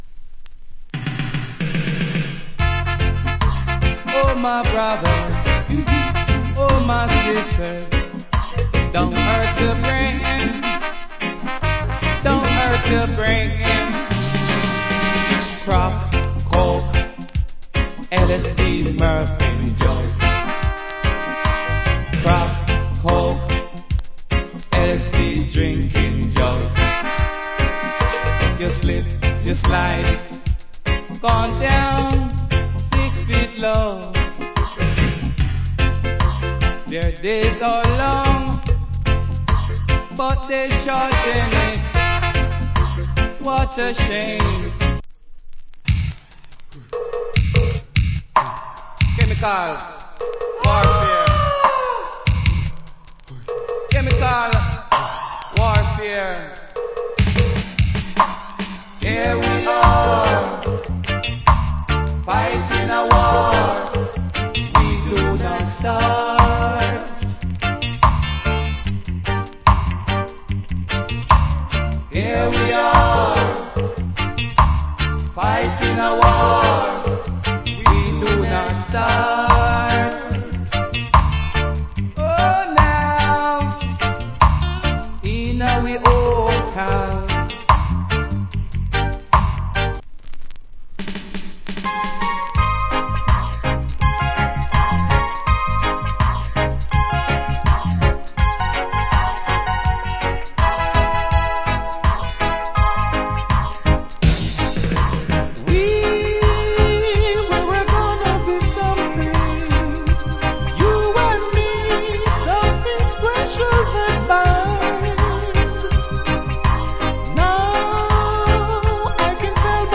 ROOTS ROCK
80's Good Roots Rock Album.